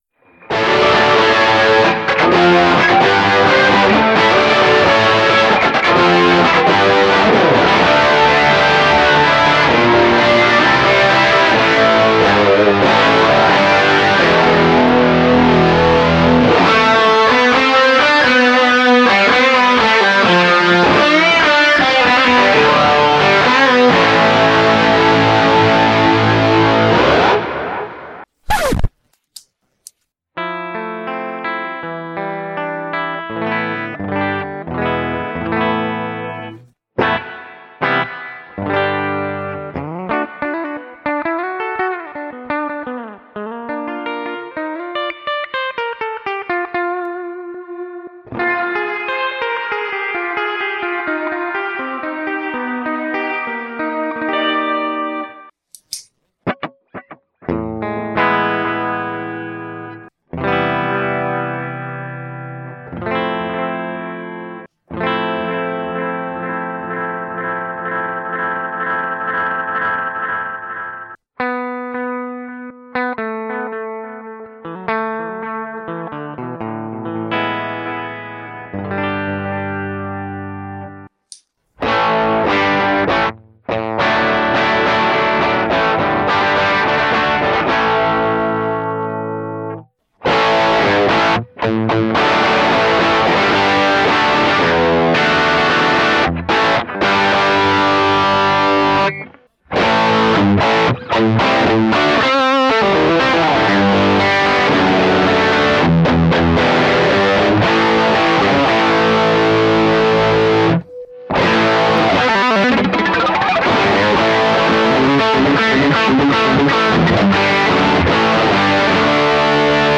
Sonicake Sonicbar Rock Stage Multi Effects Pedal, Classic Rock Tone, Distortion, Chorus, Delay, Reverb, Cab Sim, QCE10
Analog Plexi Style Distiortion Brings the Tone back to 80s/90s Hard Rock Stage
BBD-style Analog-sounding Chorus adds a Beautiful Warm-Dream feeling to the Sound
500ms Analog-Voicing Delay & High Quality Reverb Expand the Tone into Grand Spacy Timbre